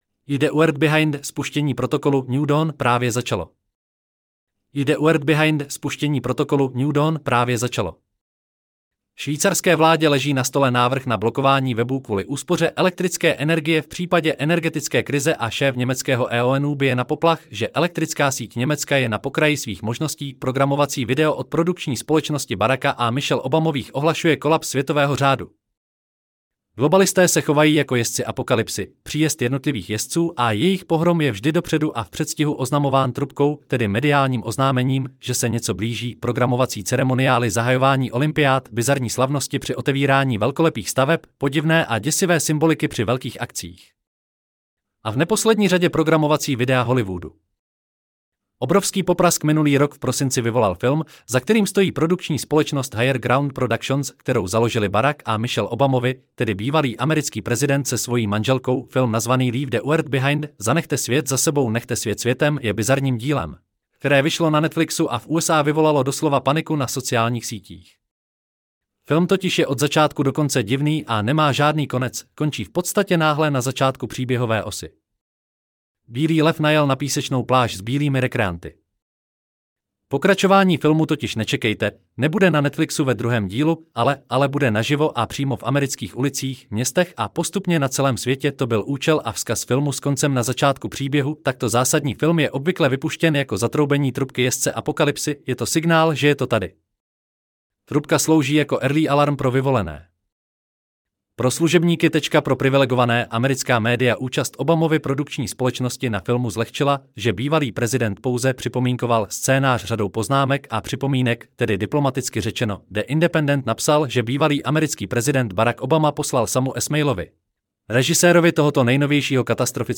Celý článek si můžete poslechnout v audioverzi zde: LEAVE-THE-WORLD-BEHIND_-SPUSTENI-PROTOKOLU-NEW-DAWN-PRAVE-ZACALO 25.2.2024 Leave The World Behind: Spuštění protokolu “New Dawn” právě začalo!